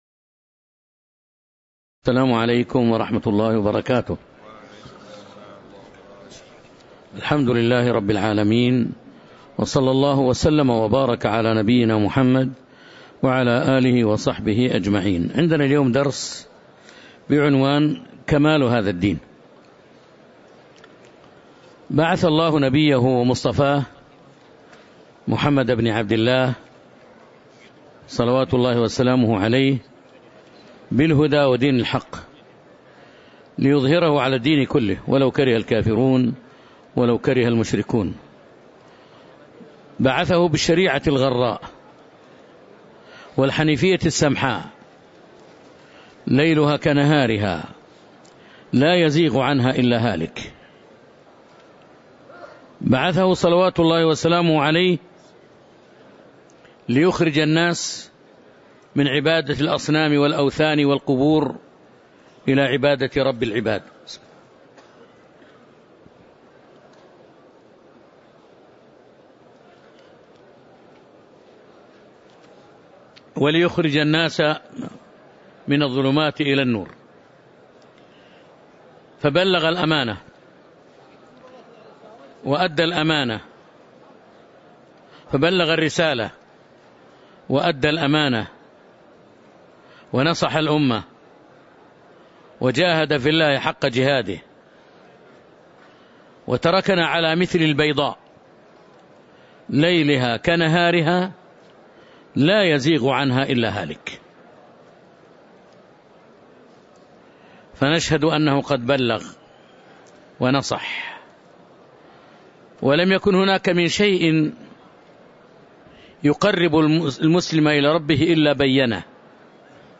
تاريخ النشر ٢٩ جمادى الآخرة ١٤٤٦ هـ المكان: المسجد النبوي الشيخ